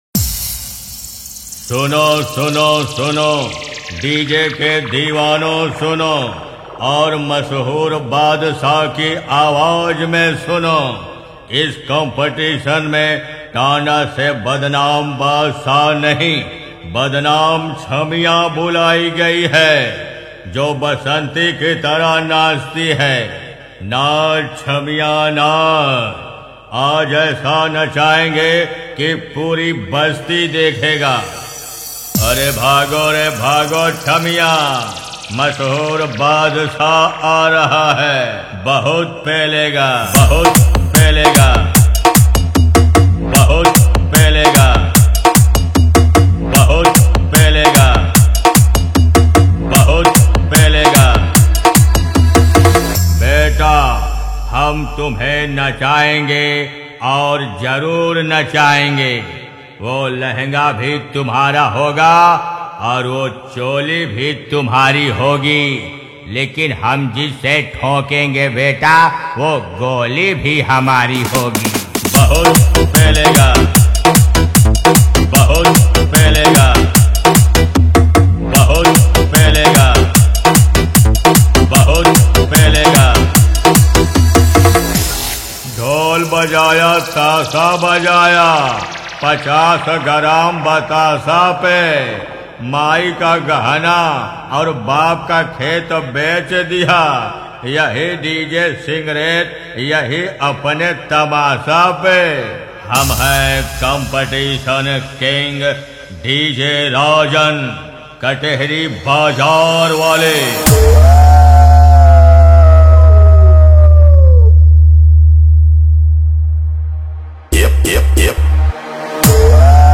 DJ Competition Songs Latest DJ Remix Mp3 Song Download
Category : DJ Competition Songs